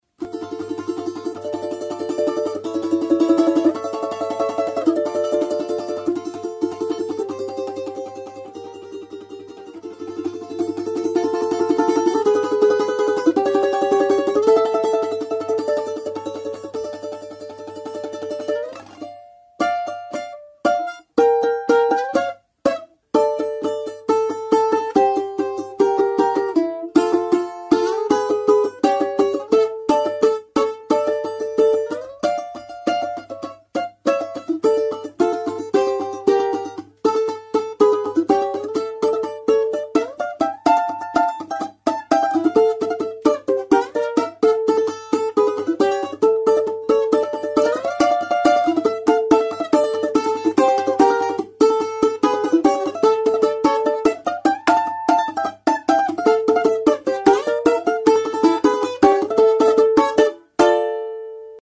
под балалайку